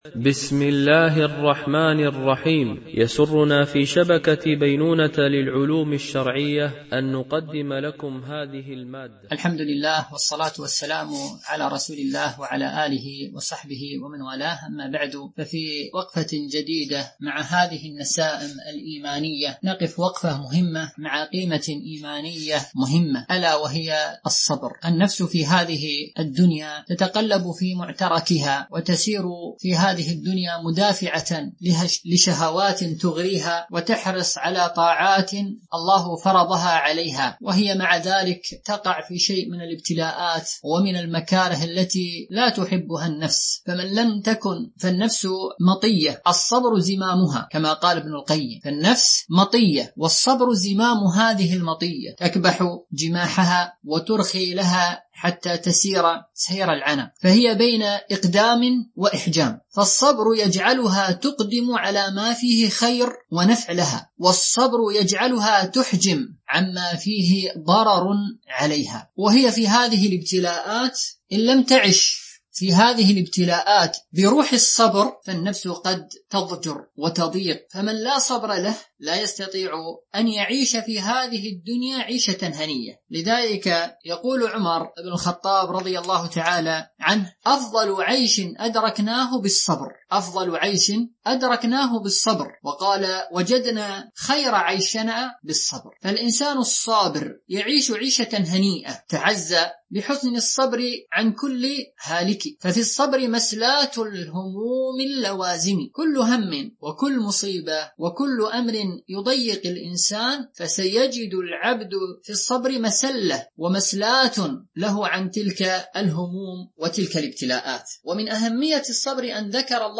سلسلة محاضرات نسائم إيمانية وقيم أخلاقية